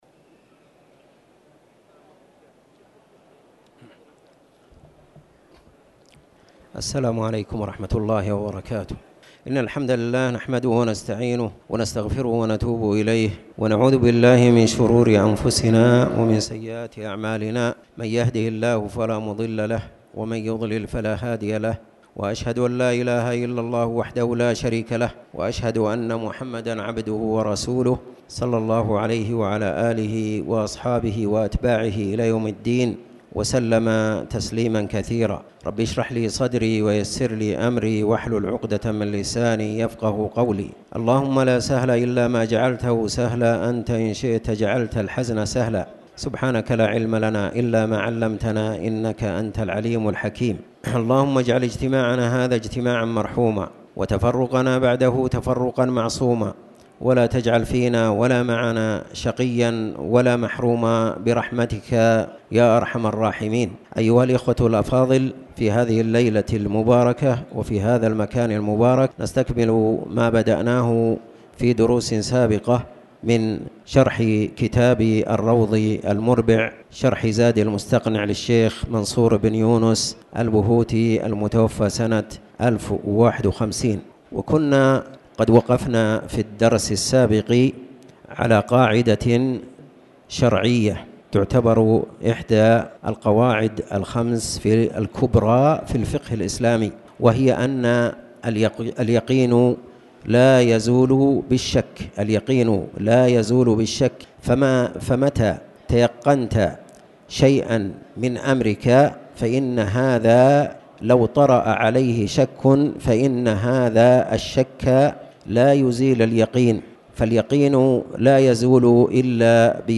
تاريخ النشر ٢٨ ربيع الثاني ١٤٣٩ هـ المكان: المسجد الحرام الشيخ